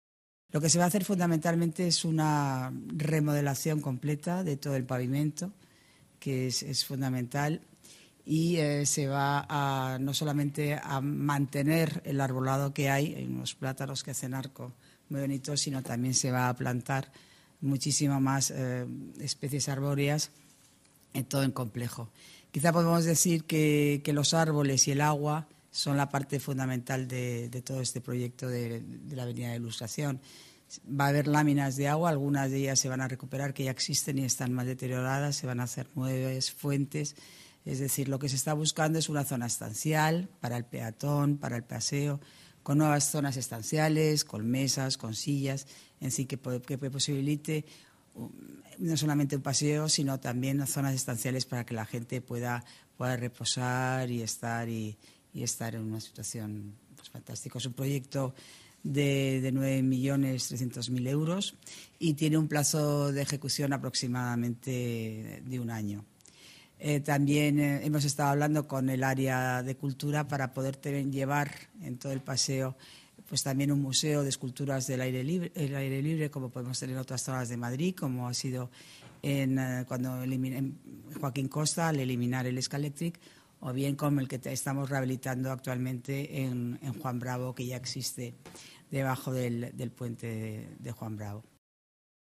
Nueva ventana:Declaraciones de la delegada de Obras y Equipamientos, Paloma García, durante la rueda de prensa posterior a la Junta de Gobierno